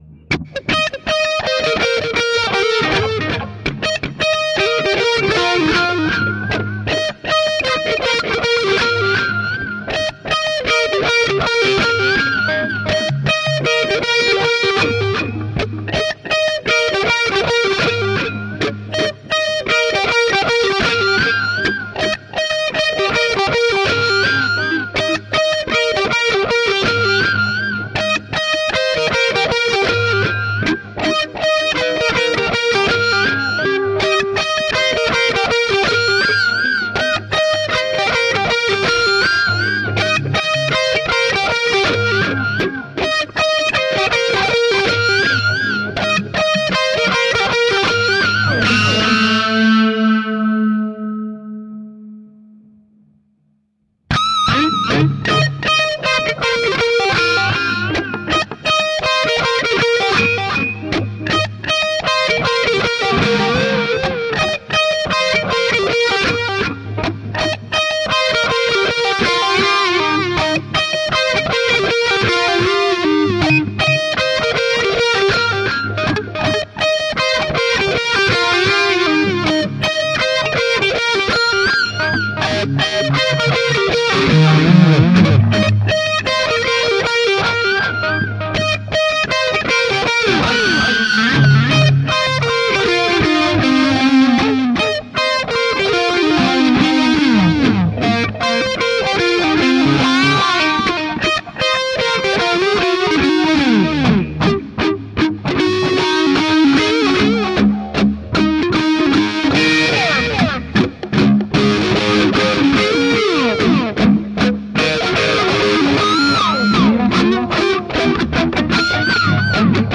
空间延迟阶段 Eflat
描述：电吉他，高增益，延迟和移相器。击中一个音符谐波，让它在其余音符上响起。幻想和空间
Tag: 摇滚 科幻 超速 延迟 失真 谐波 空间 电力 相位 吉他